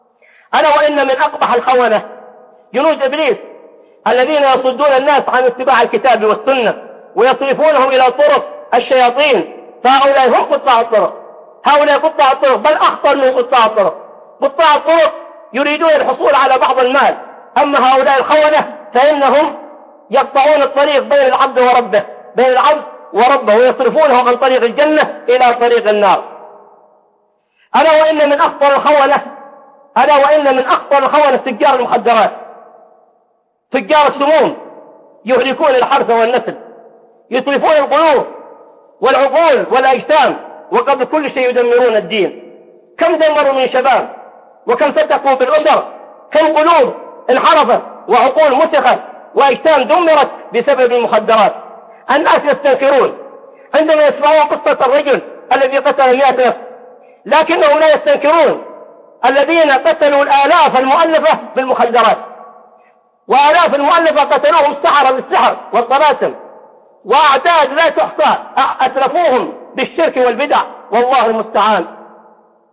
خطب ومحاضرات أخرى